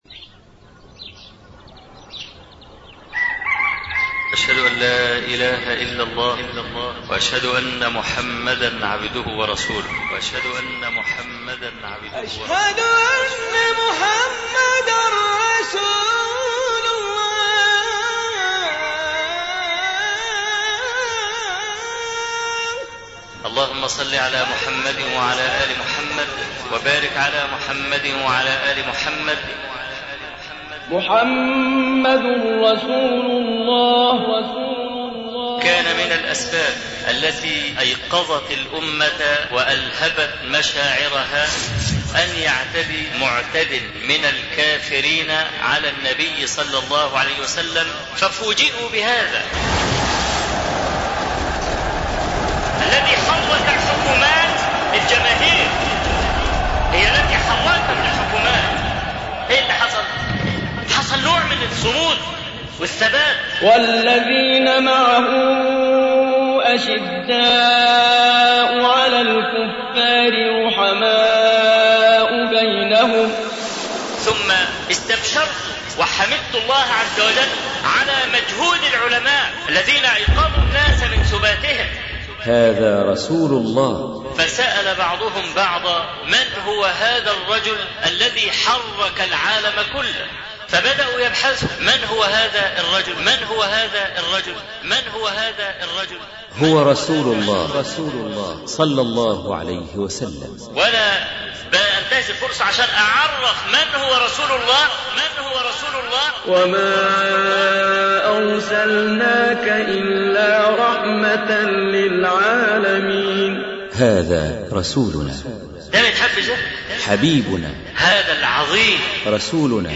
خطب ومحاضرات